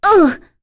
Rodina-Vox_Damage_02.wav